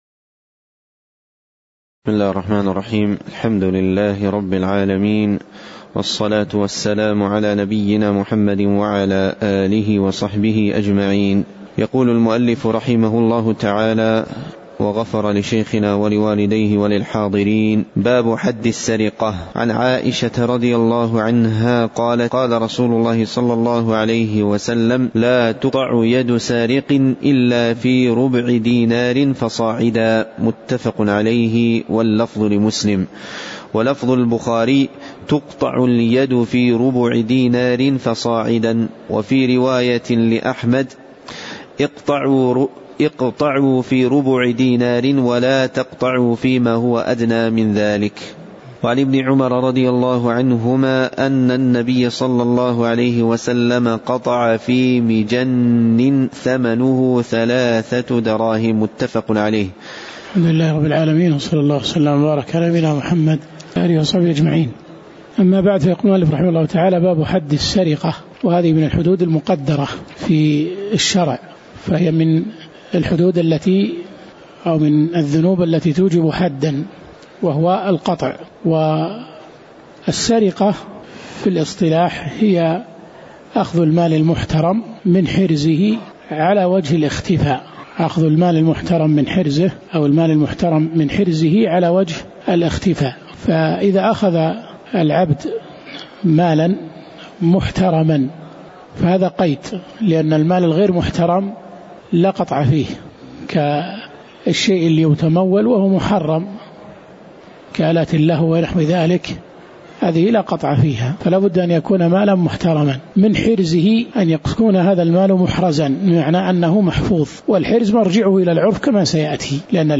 تاريخ النشر ١٥ محرم ١٤٤٠ هـ المكان: المسجد النبوي الشيخ